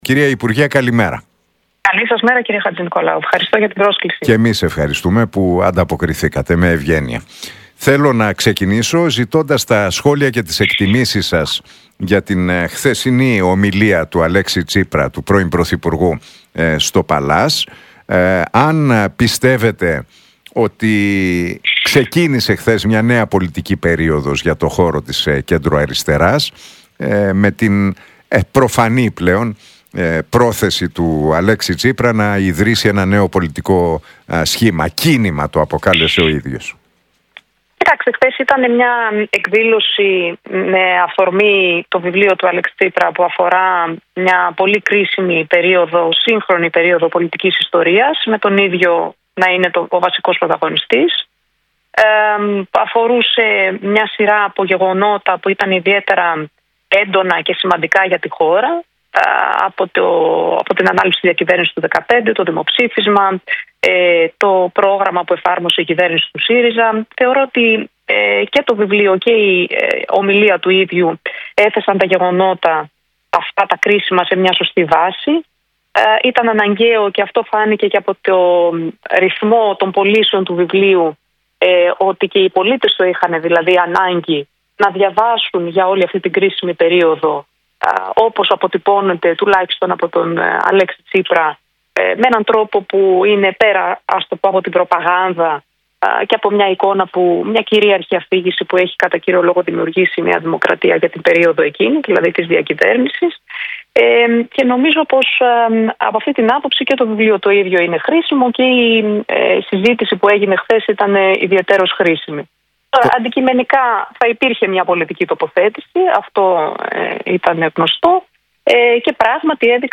Για το βιβλίο του Αλέξη Τσίπρα, την παρουσίαση χθες στο Παλλάς και τα μηνύματα του πρώην Πρωθυπουργού για τον προοδευτικό χώρο μίλησε η βουλευτής της Νέας Αριστεράς, Έφη Αχτσιόγλου στον Νίκο Χατζηνικολάου από τη συχνότητα του Realfm 97,8.